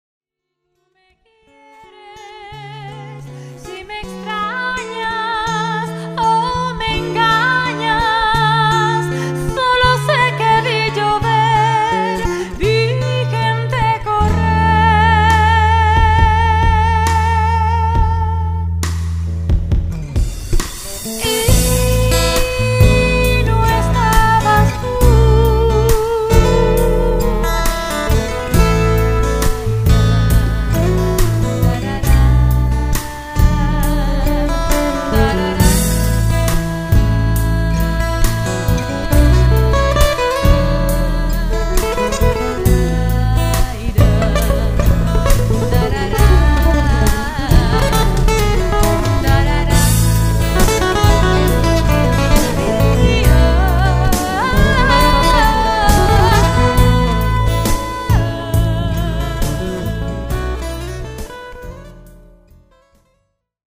Voz y Coros
Guitarras
Studio